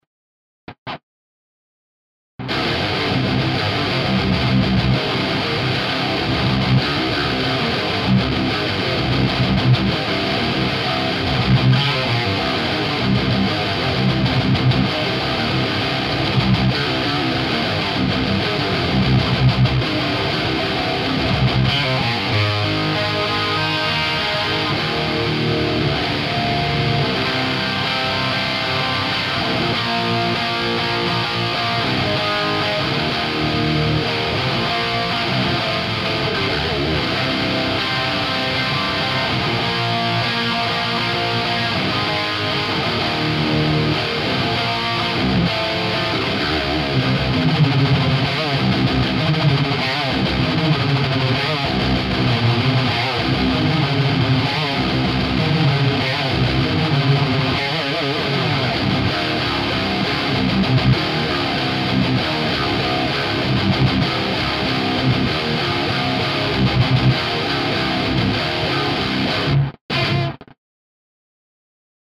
même sample passé dans une AMT R2, ce qui permet de comparer la R1 et la R2, qui ont une légère différence, comme je vous l'avais déjà écrit.
La gratte est une musicman axis , je vous laisse imaginer ça avec une gratte dans l'esprit prs ou autre acajou genre les paul ...